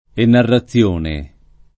vai all'elenco alfabetico delle voci ingrandisci il carattere 100% rimpicciolisci il carattere stampa invia tramite posta elettronica codividi su Facebook enarrazione [ enarra ZZL1 ne ] s. f. — raro latinismo lett. per «narrazione minuta» (o sim.)